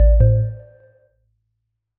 Melodic Power On 2.wav